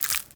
Sfx_creature_penguin_foot_ice_07.ogg